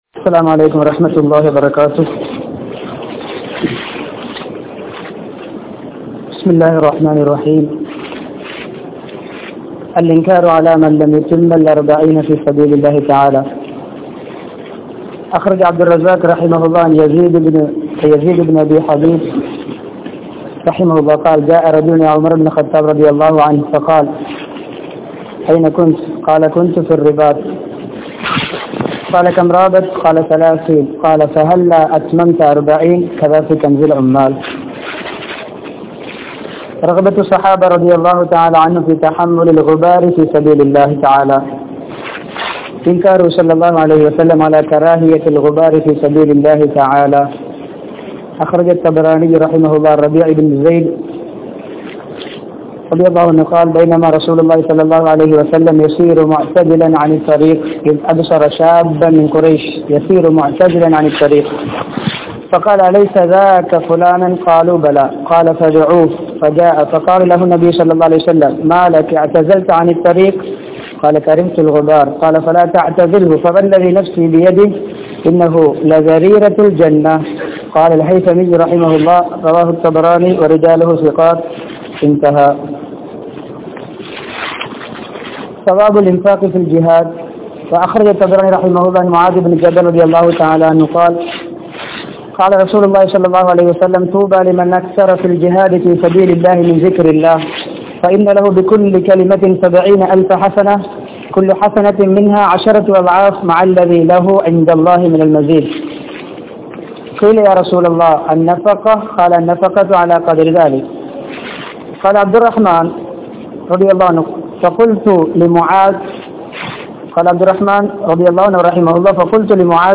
49Koadi Nanmai Kidaikkum Amal (49கோடி நண்மை கிடைக்கும் அமல்) | Audio Bayans | All Ceylon Muslim Youth Community | Addalaichenai